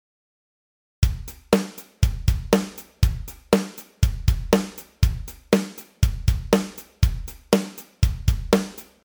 8ビートの基本パターン1
もっとも基本となる、ドッタッ　ドドタッのパターンです。
8beat1.mp3